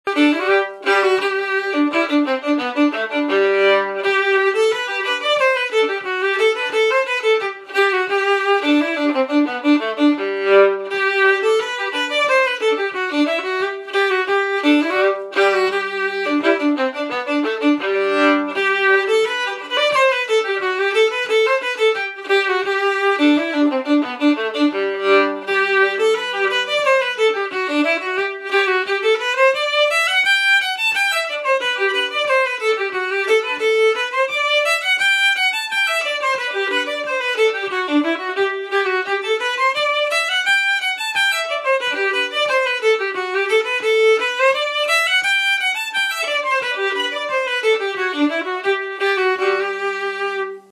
Key: G
Form: Reel
Region: Québecois/Acadian
Esquimeux-crooked-version.mp3